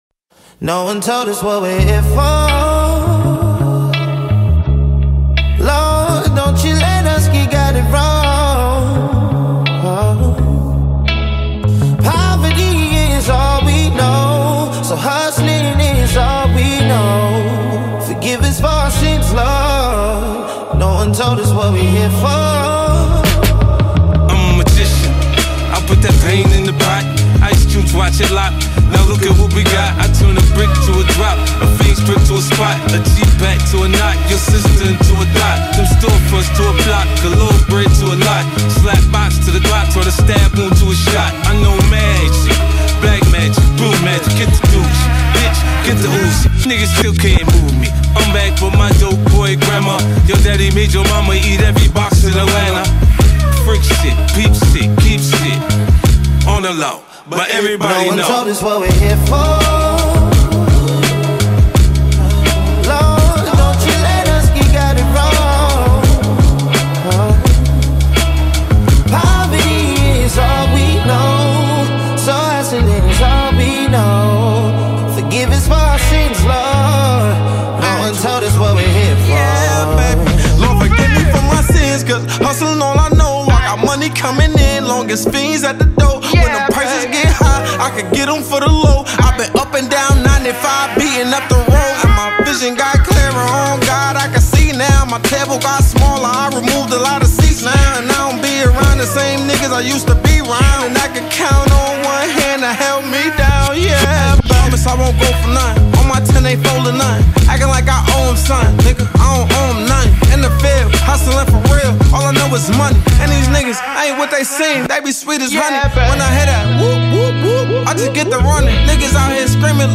Extended Version